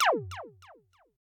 sfx_laser2.ogg